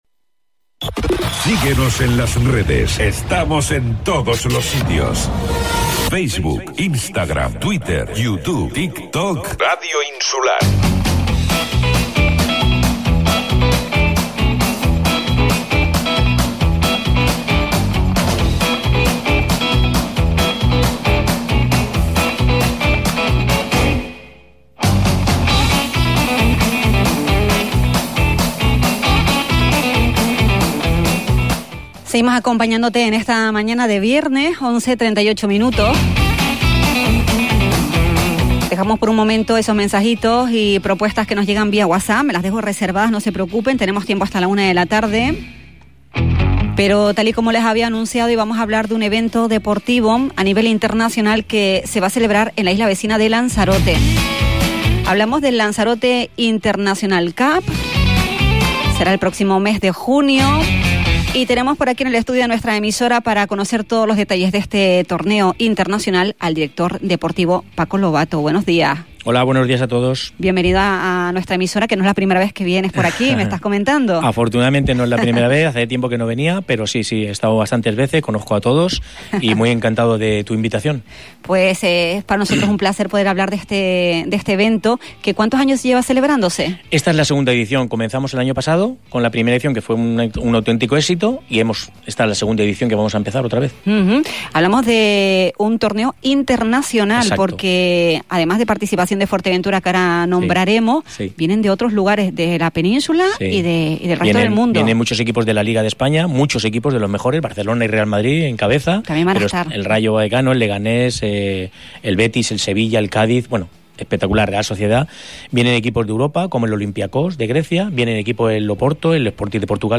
en una entrevista concedida este viernes al programa La Mañana Xtra de Radio Insular.